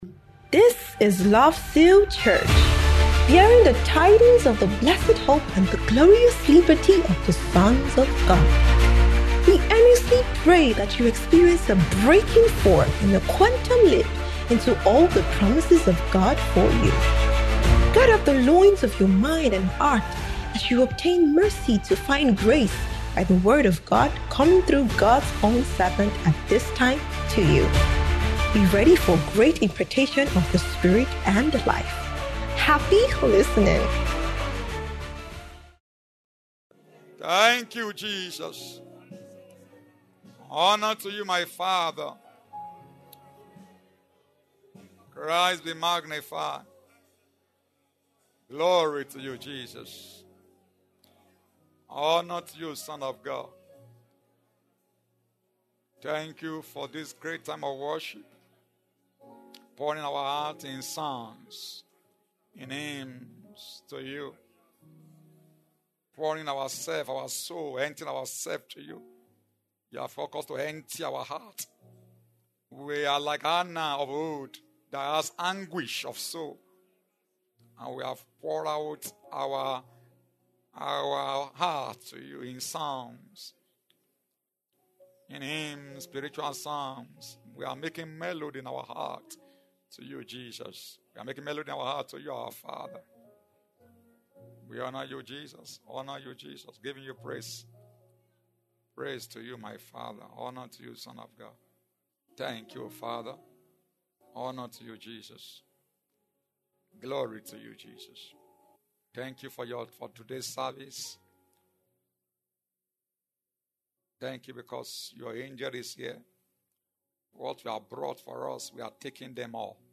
Kingdom Believers’ Community Service